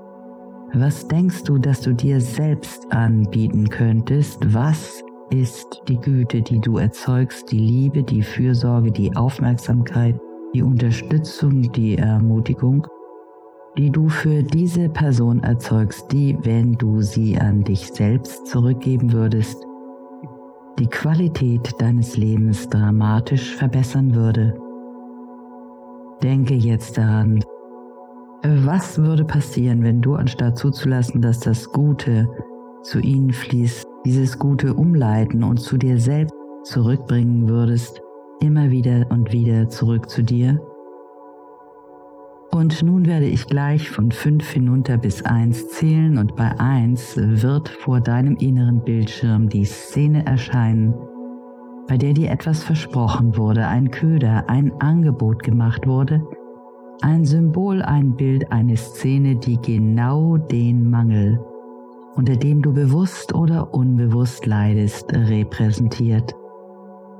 • Musik: Ja;